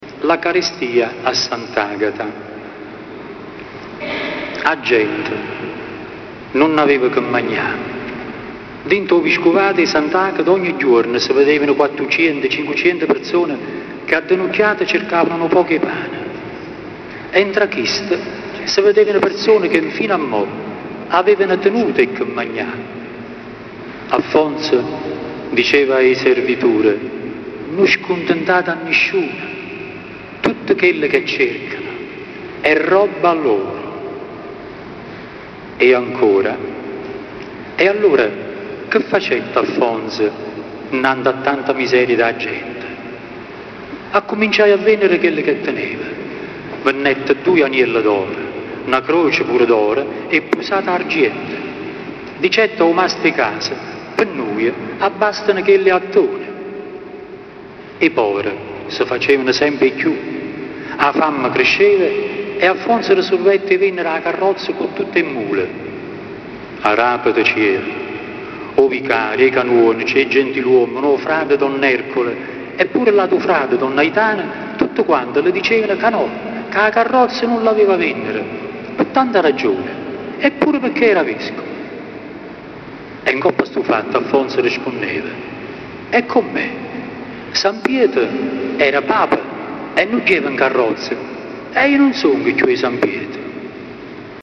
dialetto napoletano
Lettura di alcuni brani
(la registrazione è stata fatta dal vivo con… una macchina fotografica digitale)